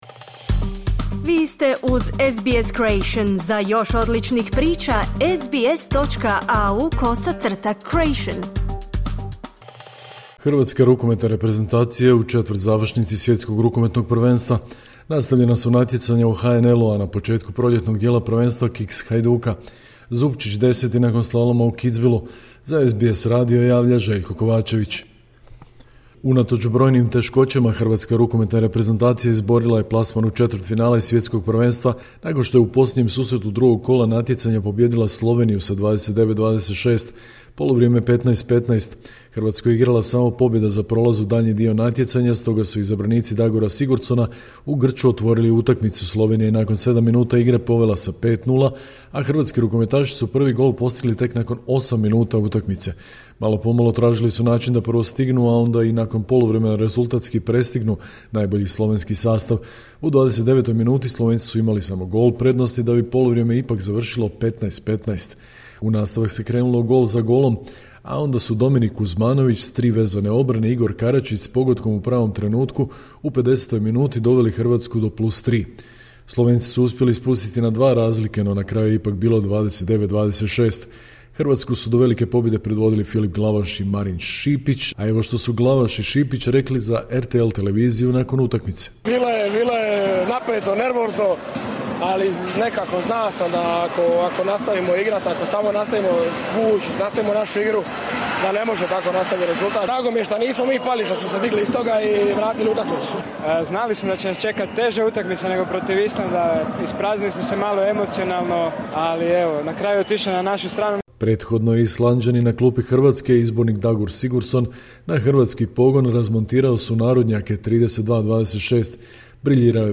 Sportske vijesti iz Hrvatske, 27.1.2025.